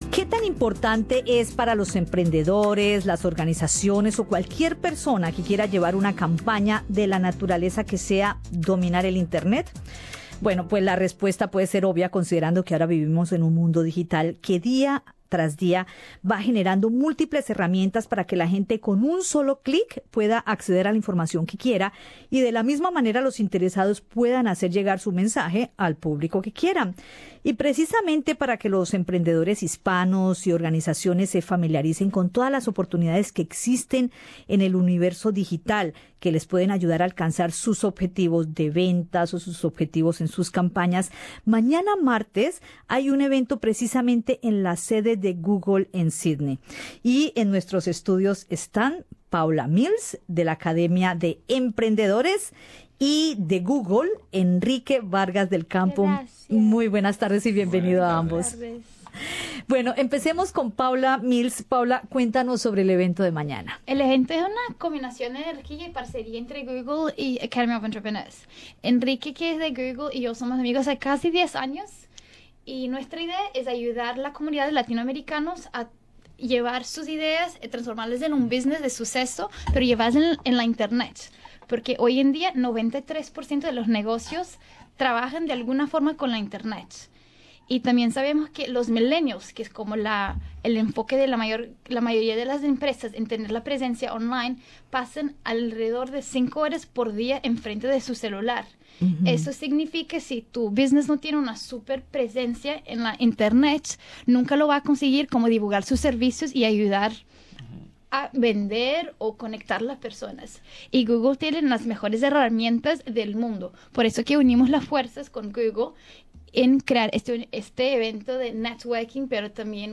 Escucha el podcast con la entrevista